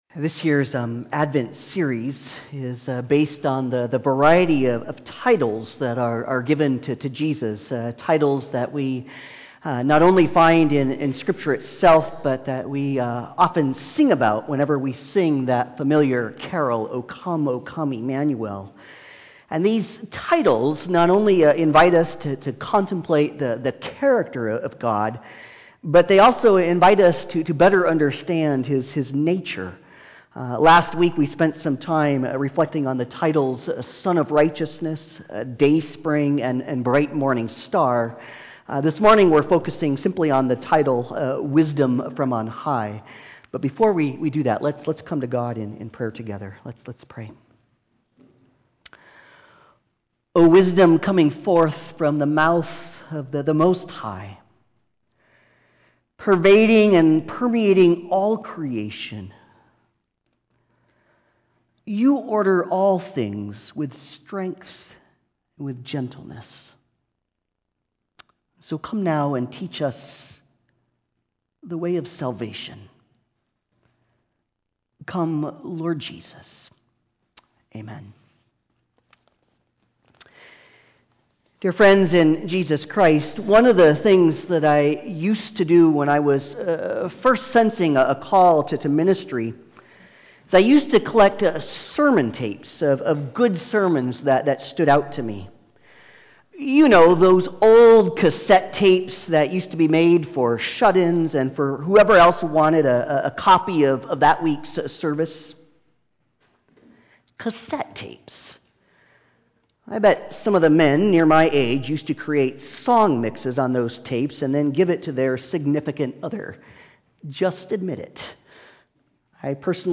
Passage: Proverbs 8:1-36, I Corinthians 1:18-25, James 3:17-18 Service Type: Sunday Service